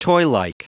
Prononciation du mot toylike en anglais (fichier audio)
Prononciation du mot : toylike